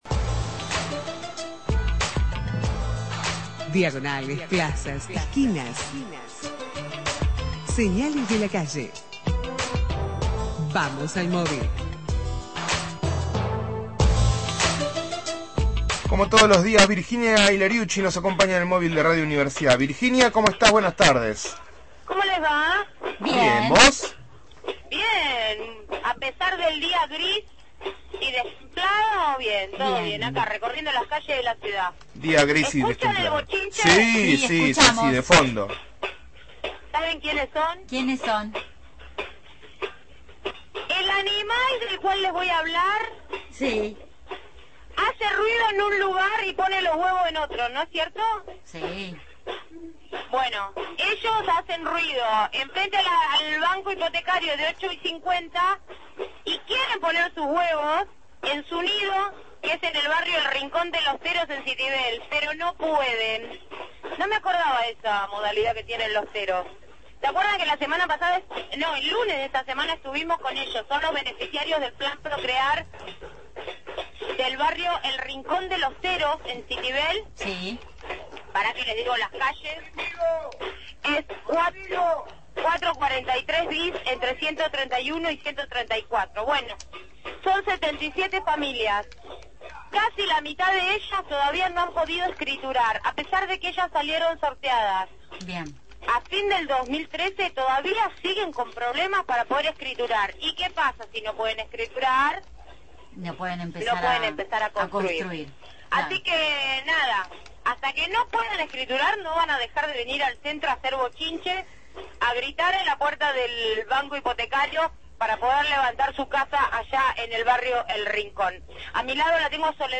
MÓVIL/ Beneficiarios del PROCREAR que no pueden escriturar – Radio Universidad